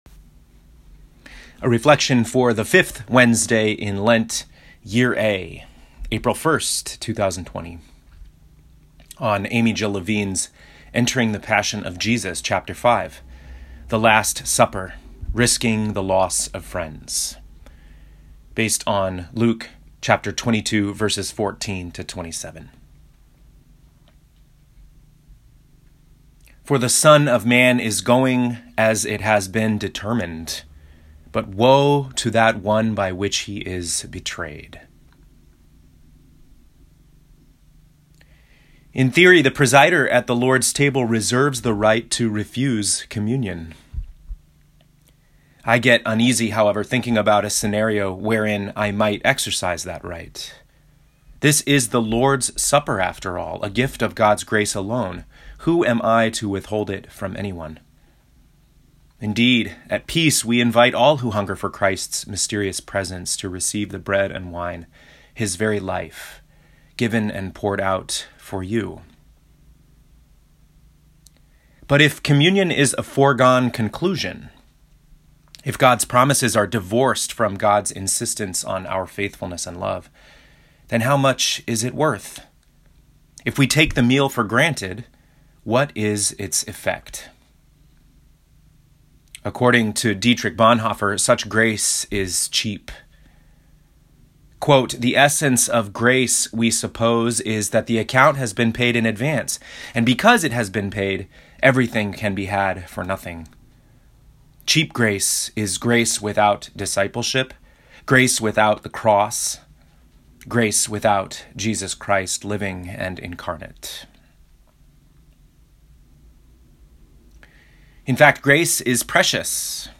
Fifth Week in Lent, Year A (4/1/20) Entering the Passion of Jesus Chapter 5: The Last Supper: Risking the Loss of Friends Luke 22:14-27 Click the play button to listen to this week’s sermon.